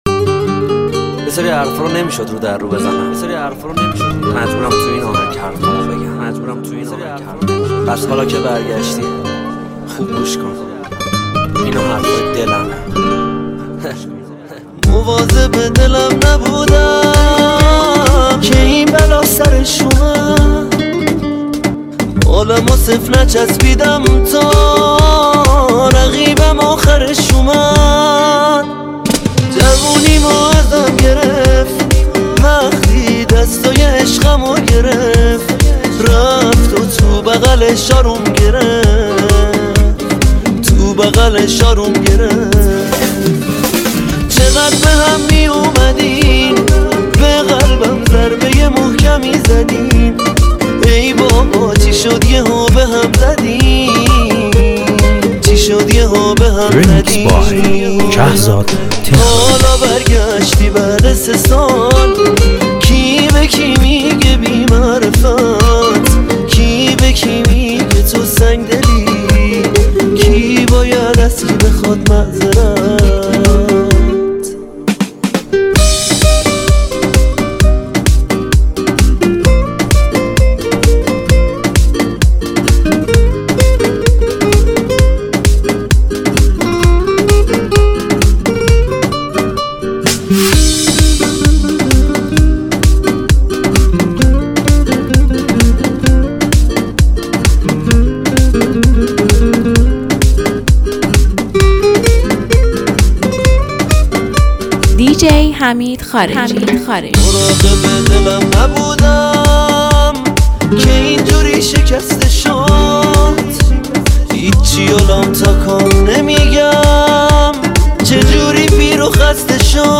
یه ریمیکس احساسی و زیبا